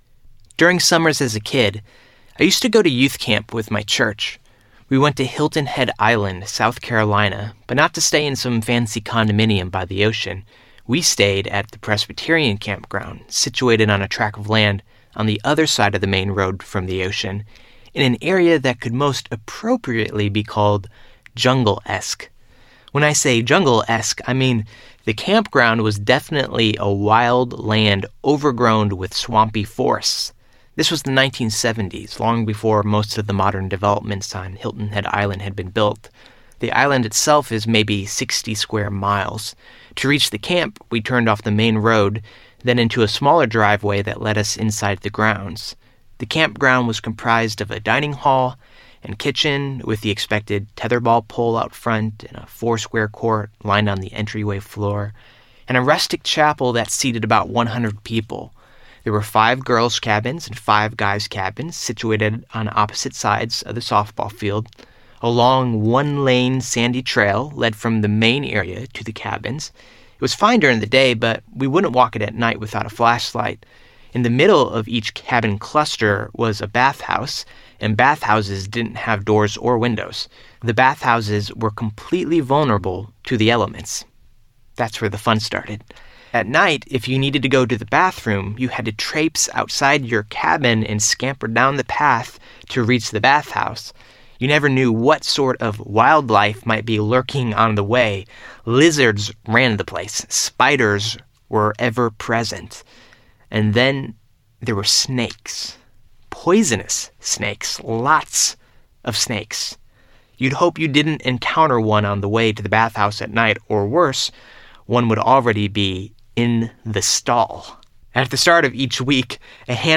Goliath Must Fall Audiobook